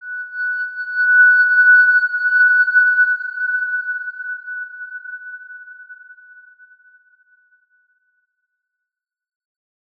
X_Windwistle-F#5-pp.wav